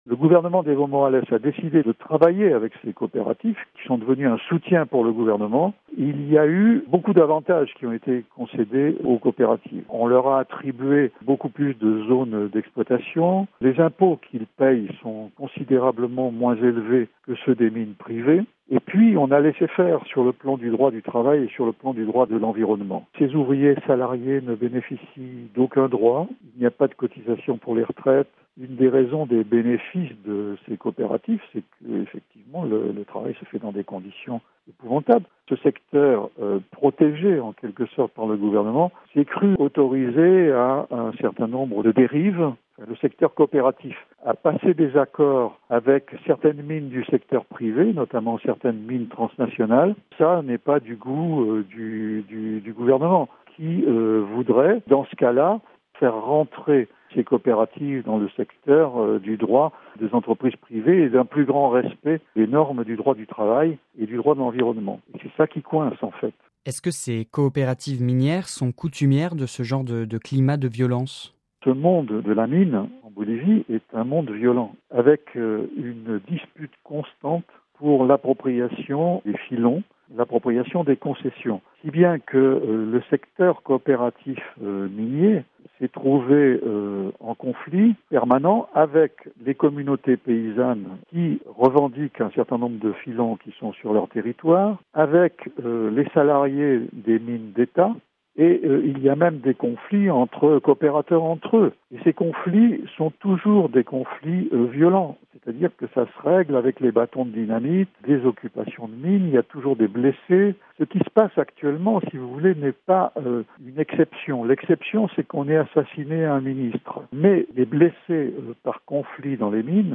(RV) Entretien - En Bolivie, la situation entre le gouvernement et les coopératives minières est toujours tendue.